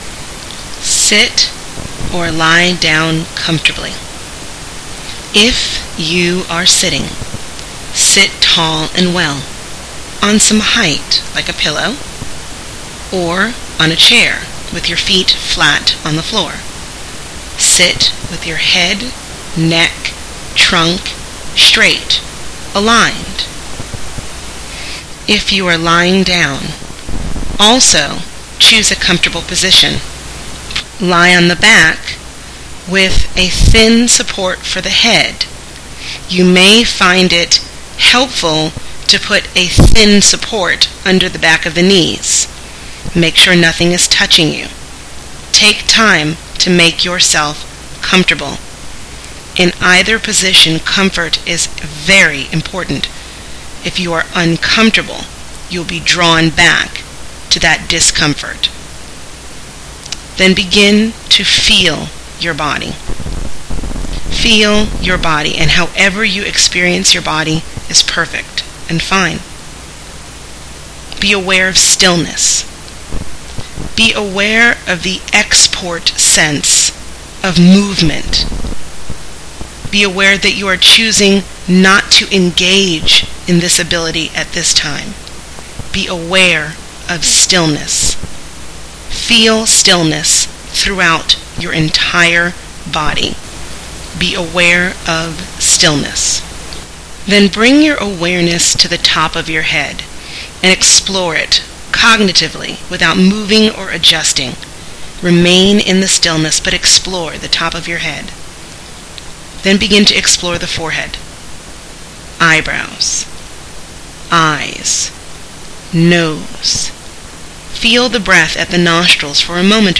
Guided Meditation – From the Gross to the Subtle
complete-relaxation.mp3